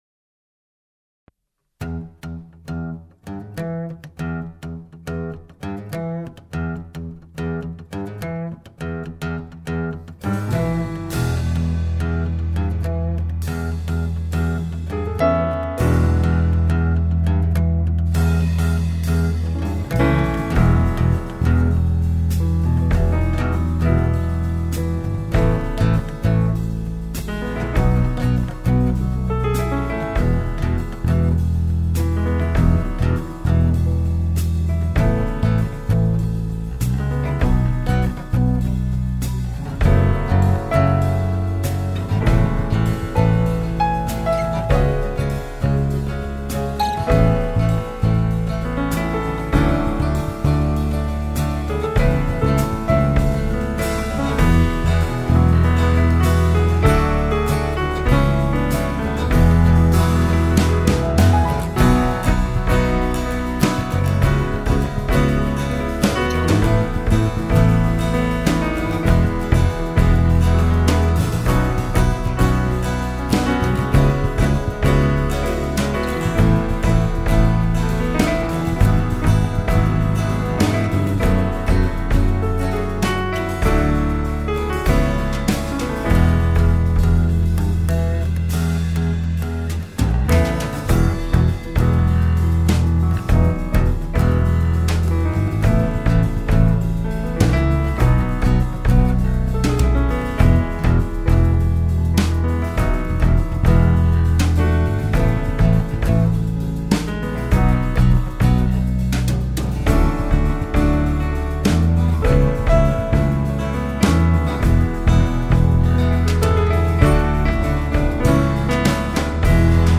. die etwas andere Coverband ...